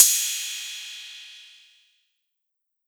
Crashes & Cymbals
Cardiak Crash 2.wav